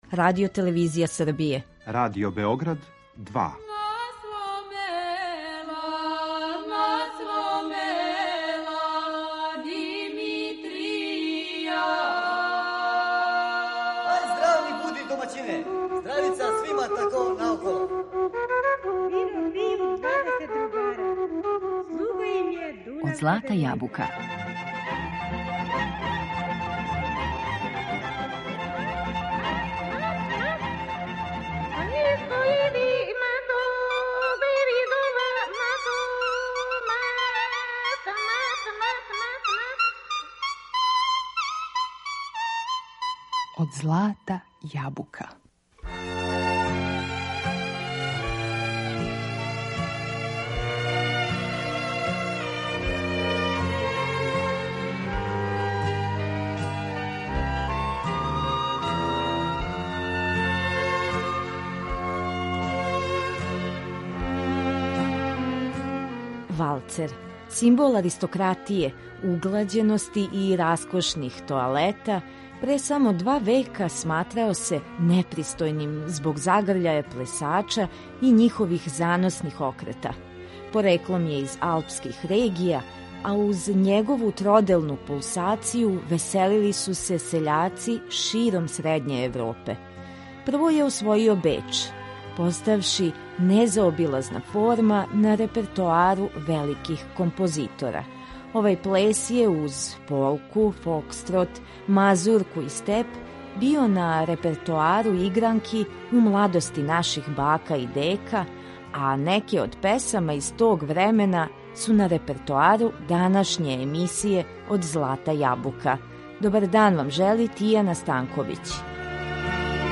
Ovaj ples je - uz polku, fokstrot, mazurku i step - bio na repertoaru igranki u mladosti naših baka i deka, a neke od pesama iz tog vremena možete čuti u današnjoj emisiji Od zlata jabuka .